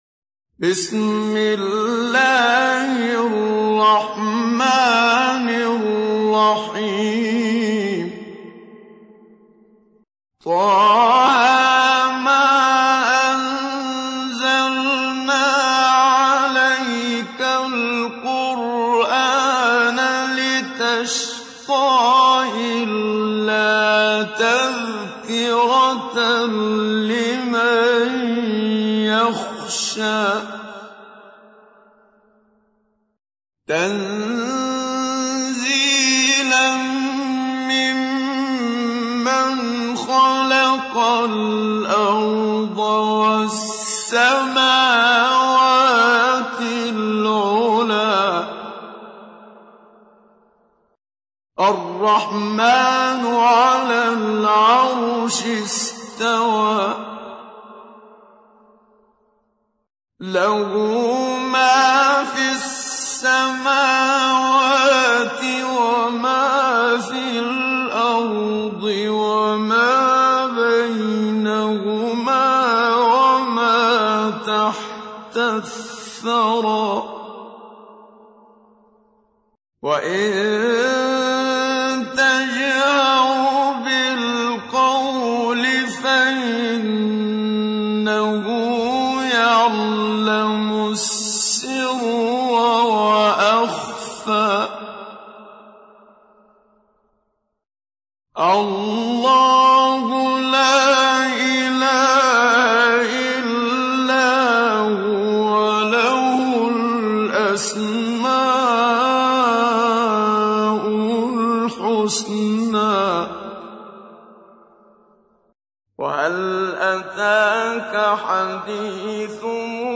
تحميل : 20. سورة طه / القارئ محمد صديق المنشاوي / القرآن الكريم / موقع يا حسين